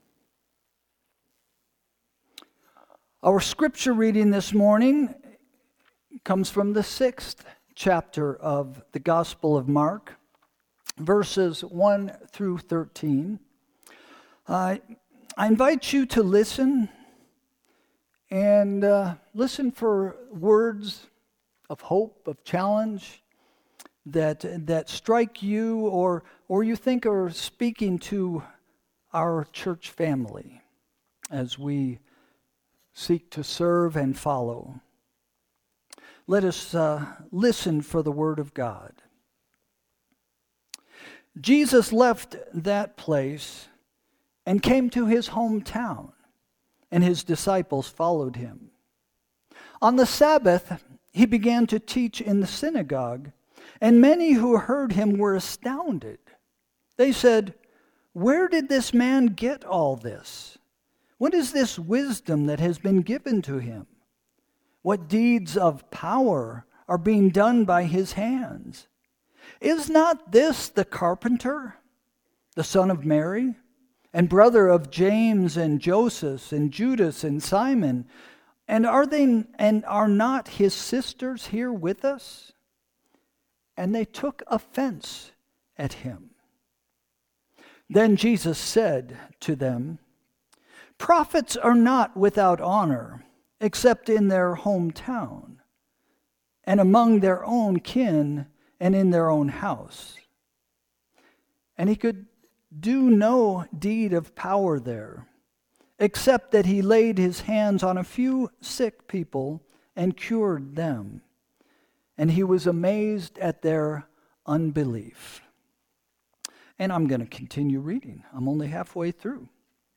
Sermon – January 18, 2026 – “Scandal”